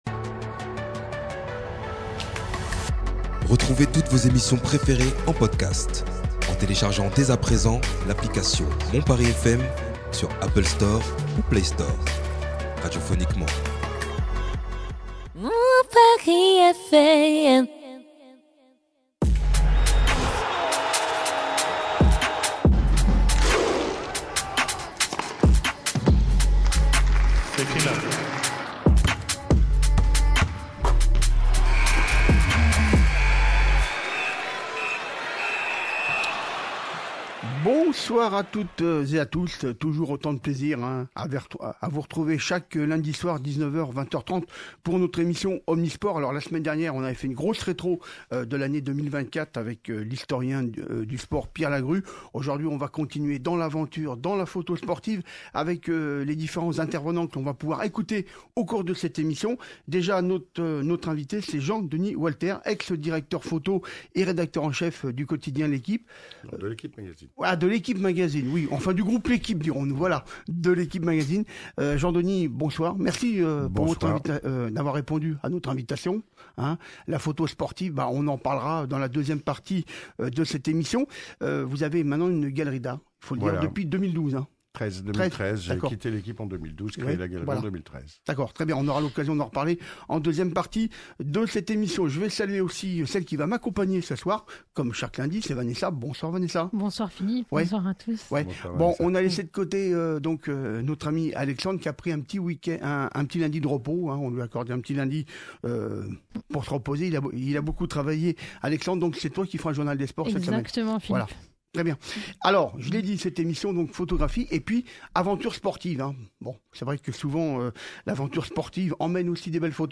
Nous écouterons une longue interview d’un explorateur du cyclisme